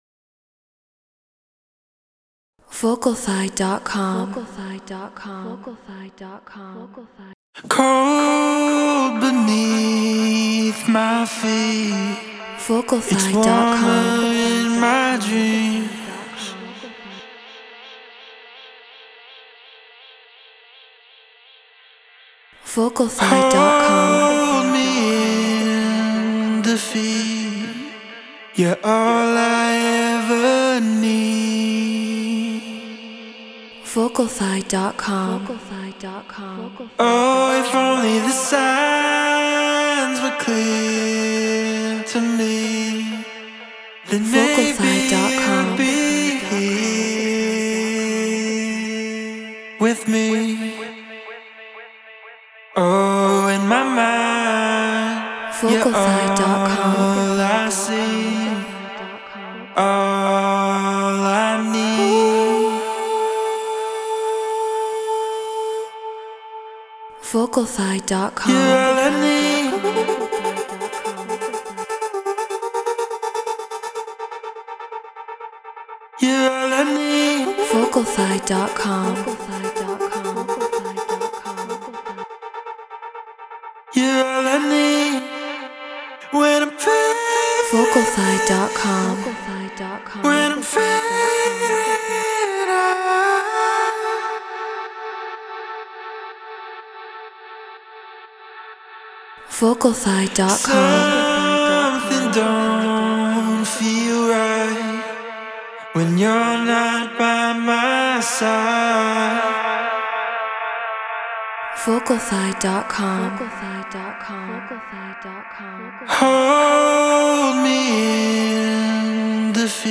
House 124 BPM Dmin